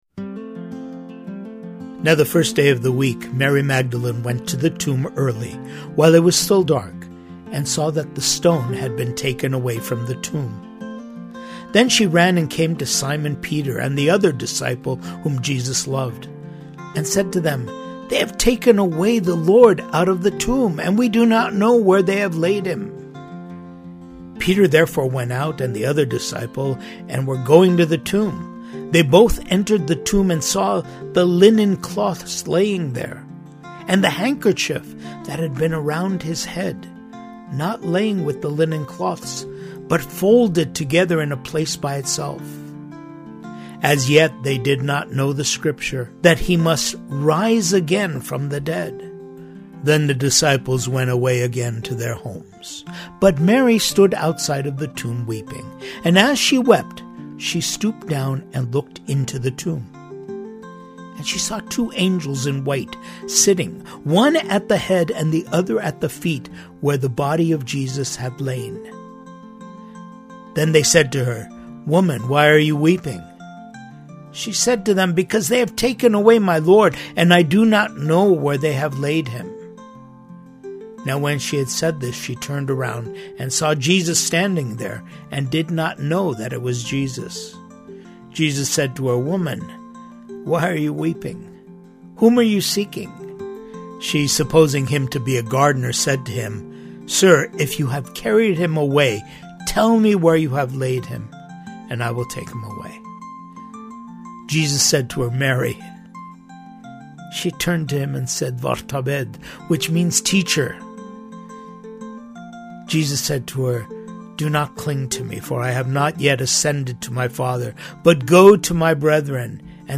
Music: The Way, Armenian Sacred Music, Hover Chamber Choir of Armenia 2005